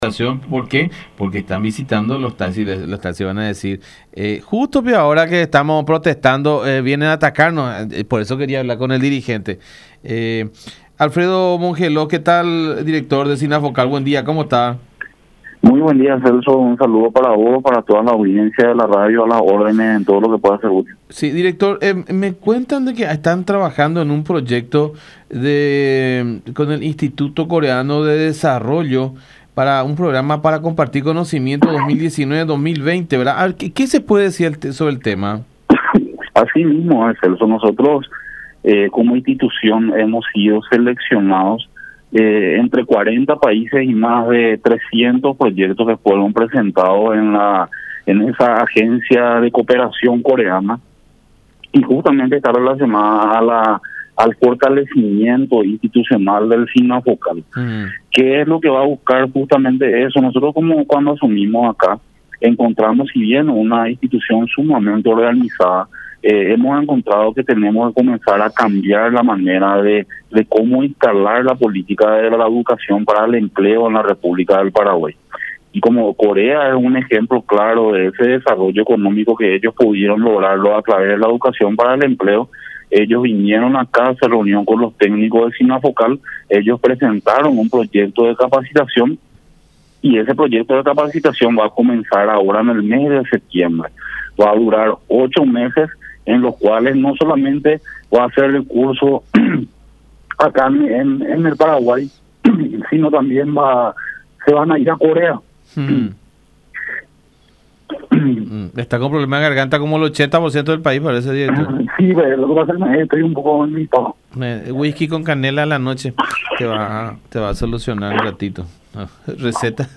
“Como institución hemos sido seleccionados, entre 40 países y más de 300 proyectos presentados, por una agencia de cooperación coreana, que busca el fortalecimiento institucional del SINAFOCAL”, destacó Alfredo Mongelós, director del ente, en comunicación con La Unión.